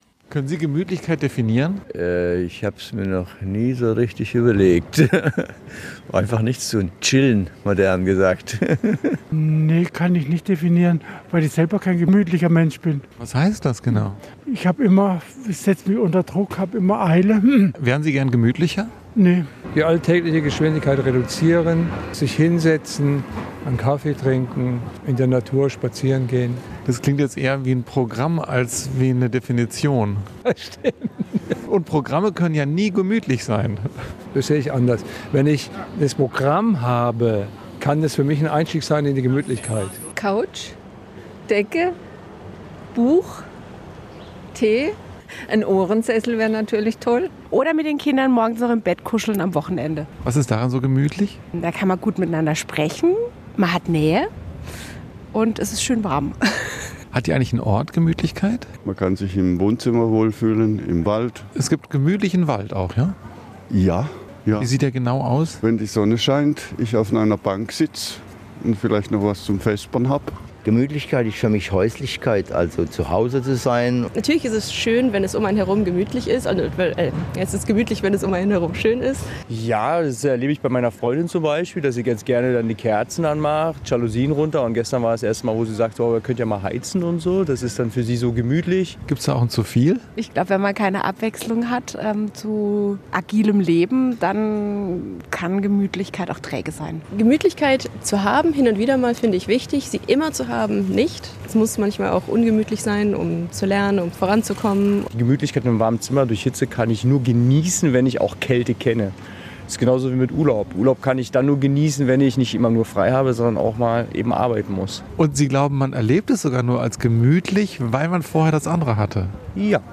Straßenumfrage: Was finden Sie gemütlich?